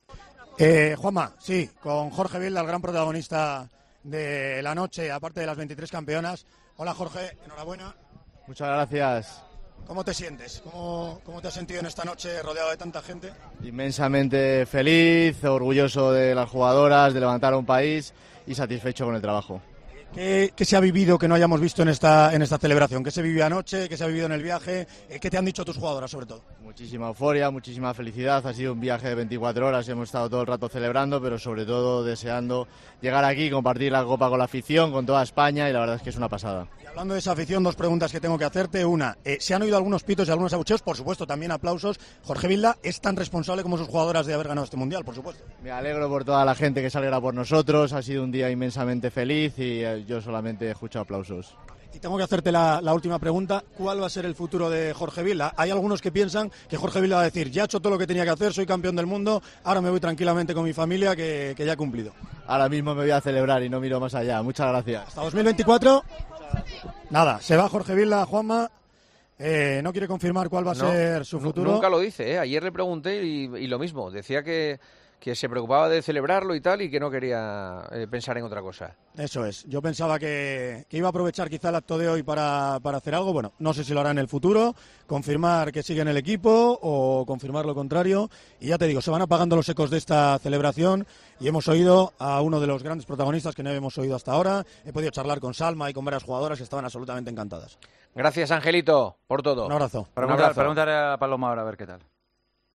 entrevistó al seleccionador nacional después de la fiesta de las campeonas ante miles de personas en Madrid.